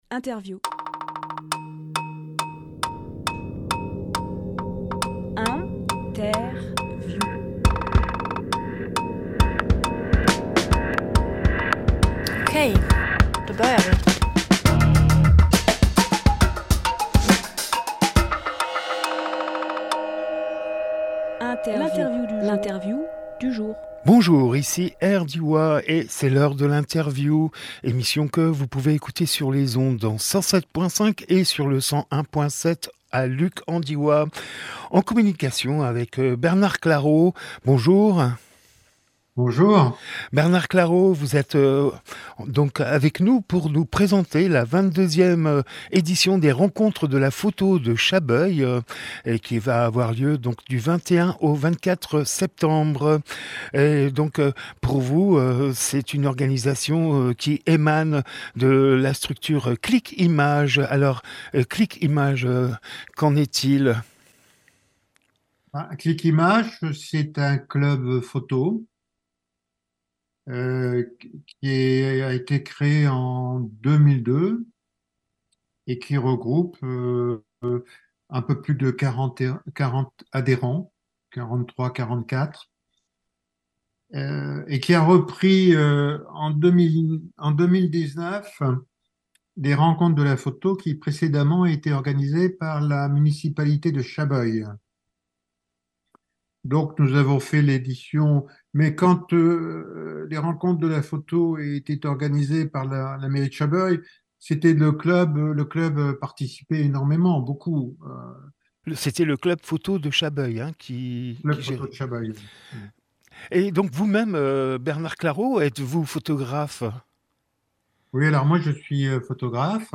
Emission - Interview Les Rencontres de la Photo à Chabeuil Publié le 15 septembre 2023 Partager sur…
06.09.23 Lieu : Studio RDWA Durée